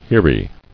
[He·re]